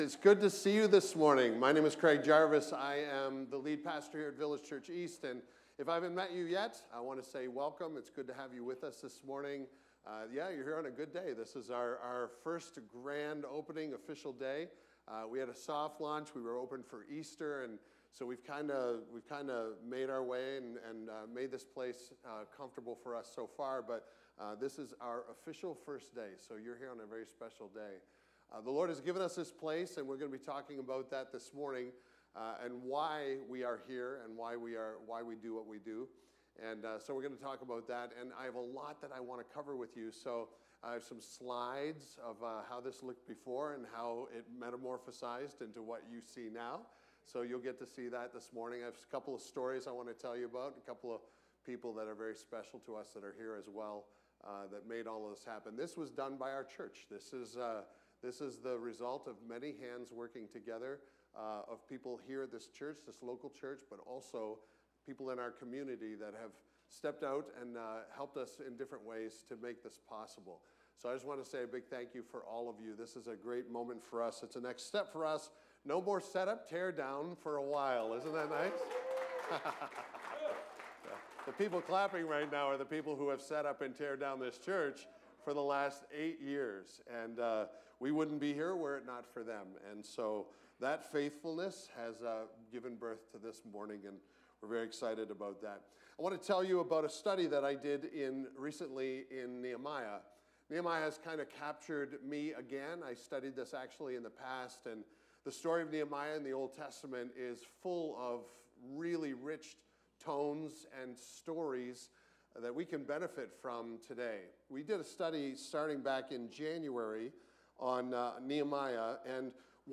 Our first official Sunday in the new building is celebrated by diving into the Word and trusting in God. We don't know what God has planned for us as a church body, but we walk by faith and not by sight.